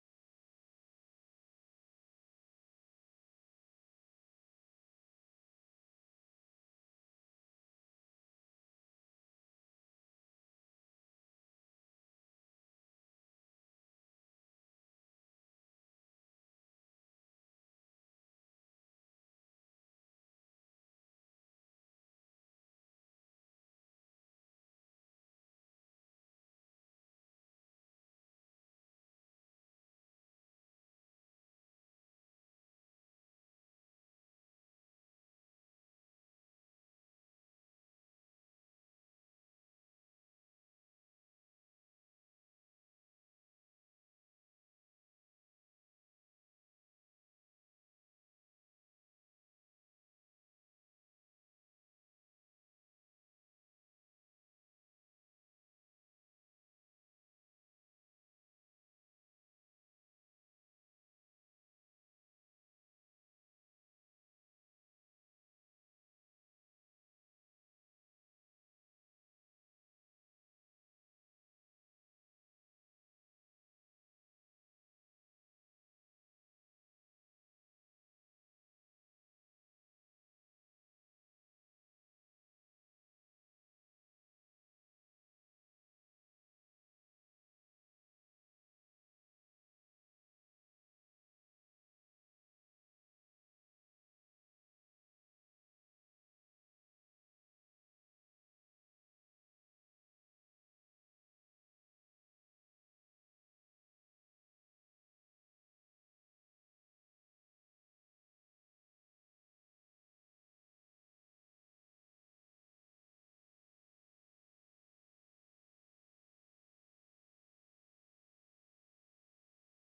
62° Ordinária da 2° Sessão Legislativa da 19° Legislatura 27/09/22 Terça Feira.
Áudio das sessões